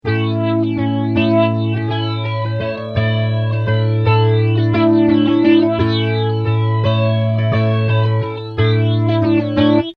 man: 9, man/auto: 3, rate: 3, recycle: 12
Clips were recorded:  Pretty guitar - effect - Bulldog Cab Sim - sound card